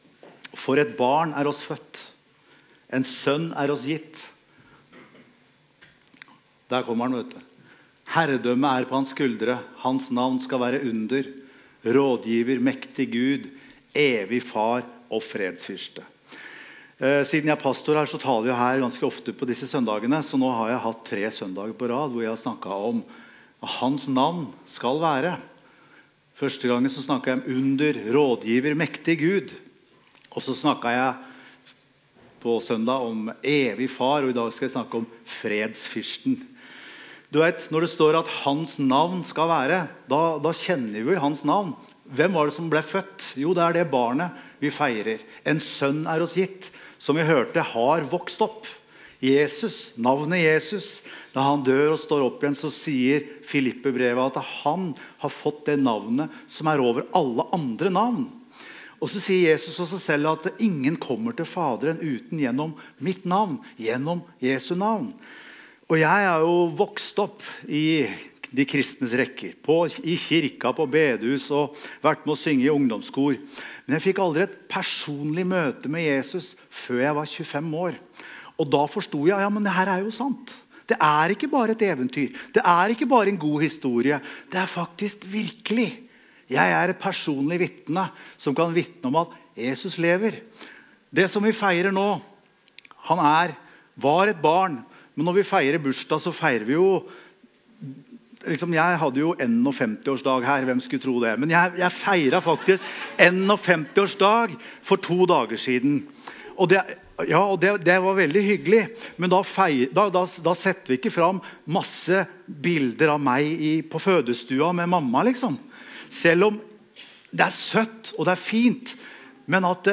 Evangeliehuset Romerike - Gudstjenester